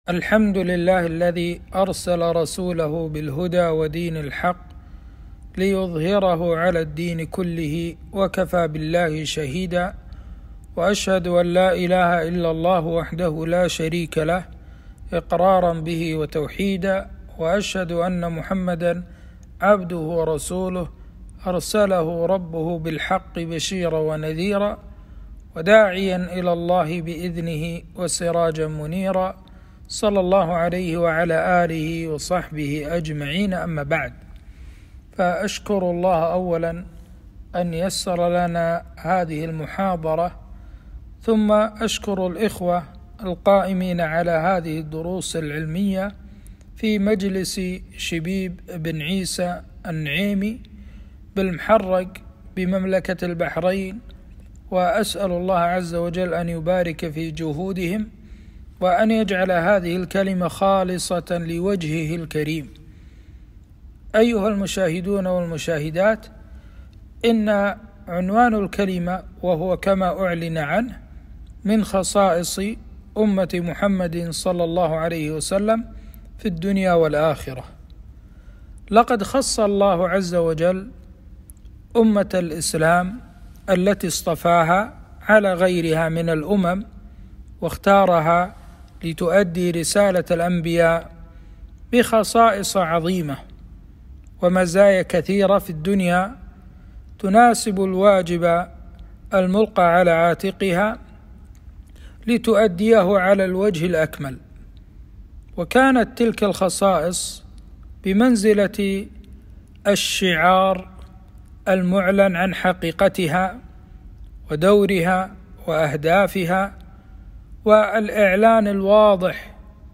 محاضرة - خصائص أمة محمد ﷺ في الدنيا والآخرة